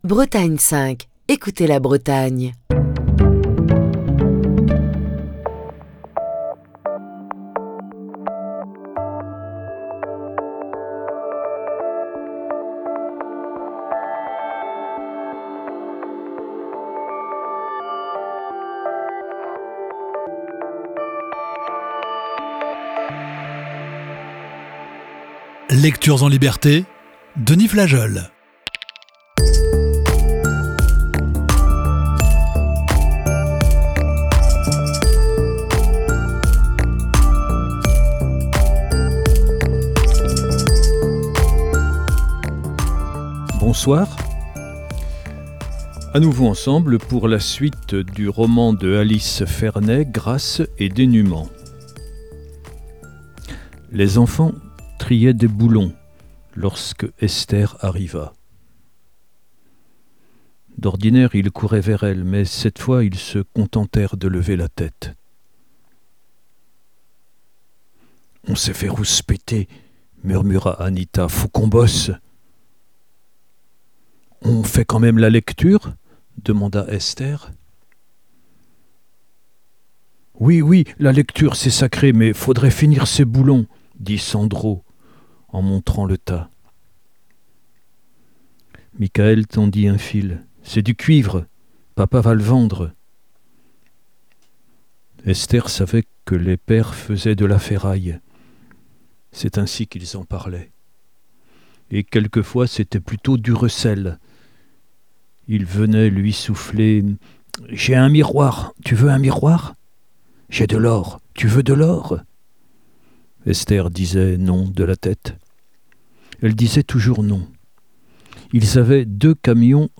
la lecture du livre